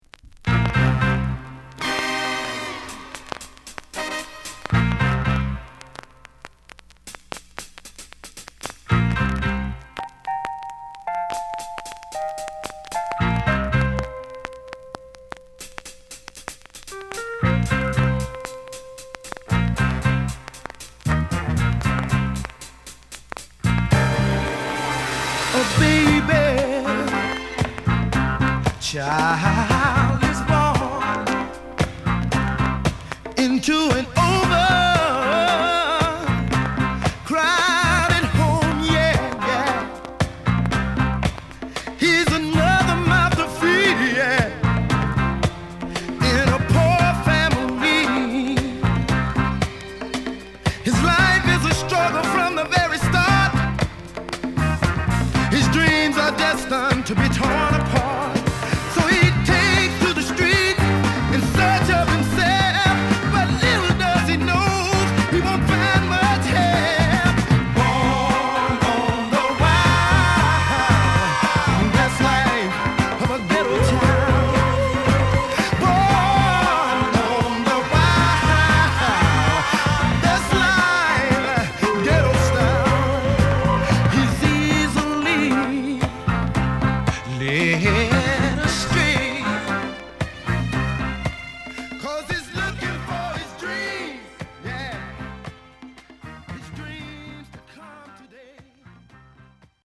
スパイムービーの様なスリリングなイントロから始まる、カッコ良いゲットーソウルを披露！